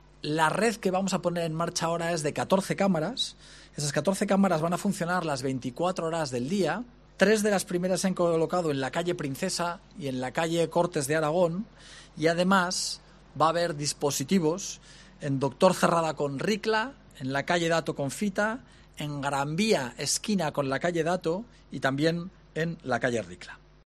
El alcalde de Zaragoza, Jorge Azcón, explica dónde van a estar ubicadas las nuevas cámaras de seguridad.